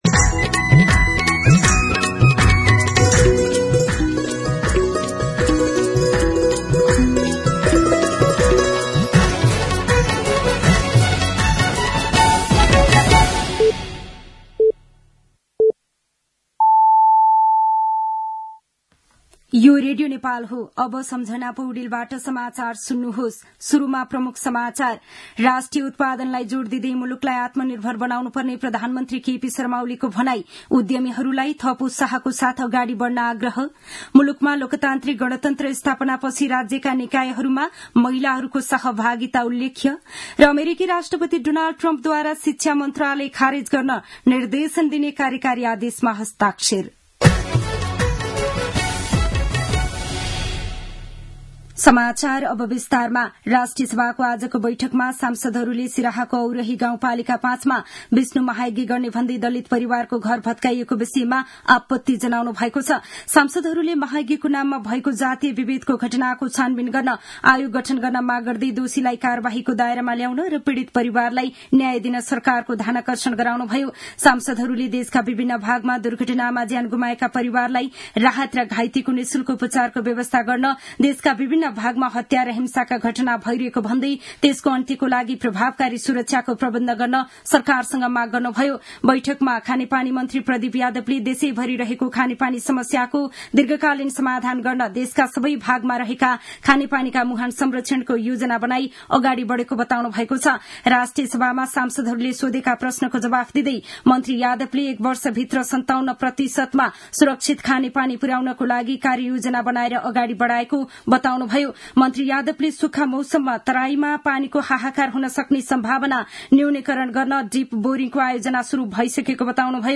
दिउँसो ३ बजेको नेपाली समाचार : ८ चैत , २०८१
3pm-news-.mp3